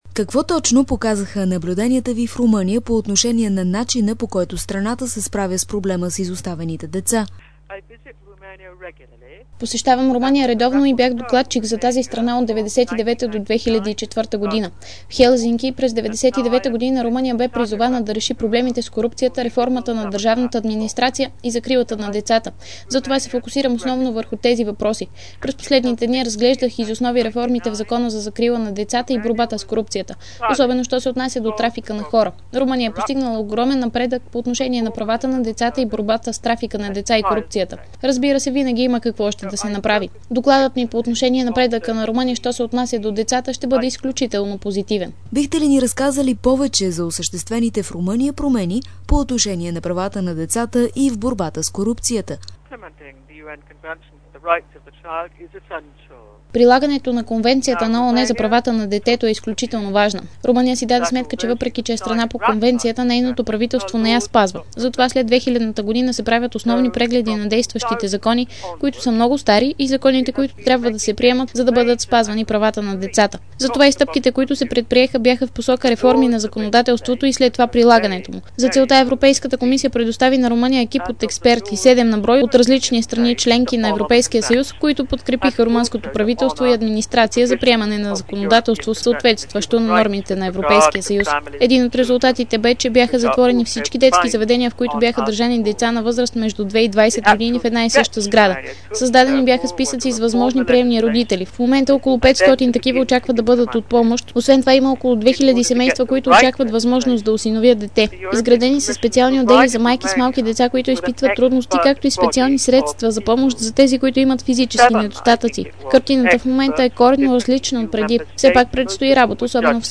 DarikNews audio: Интервю